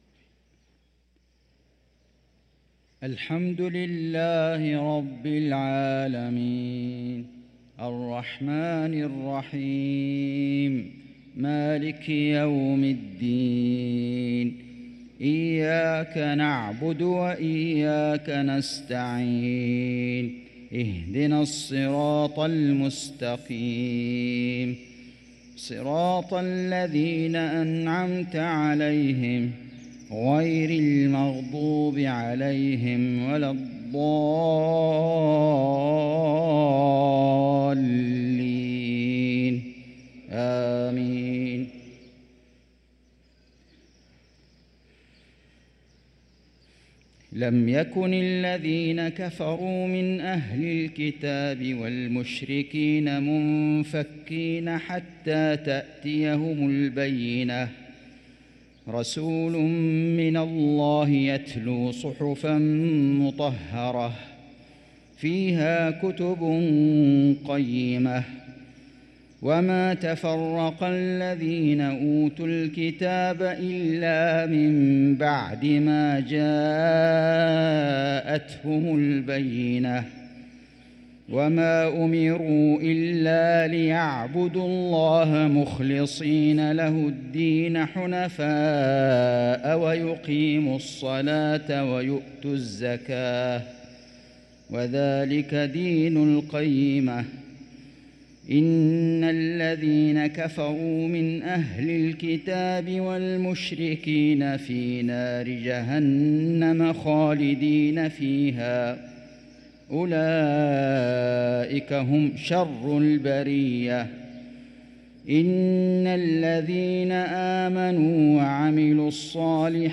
صلاة المغرب للقارئ فيصل غزاوي 19 جمادي الآخر 1445 هـ
تِلَاوَات الْحَرَمَيْن .